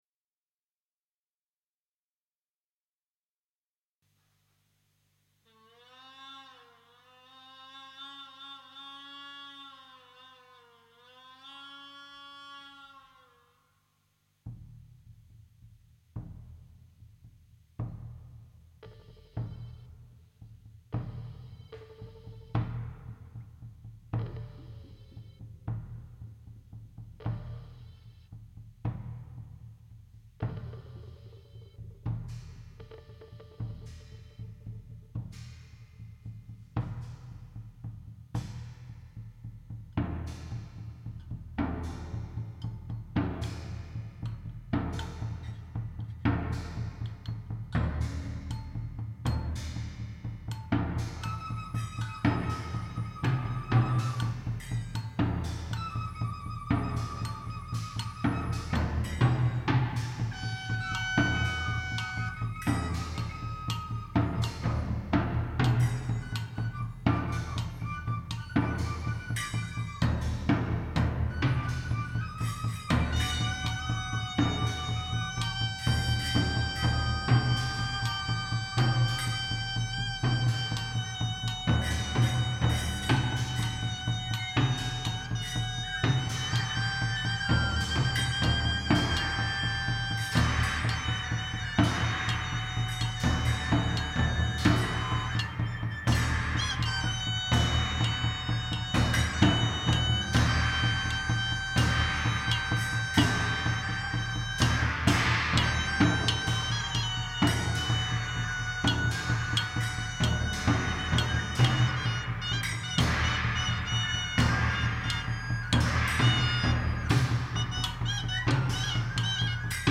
genre: free-jazz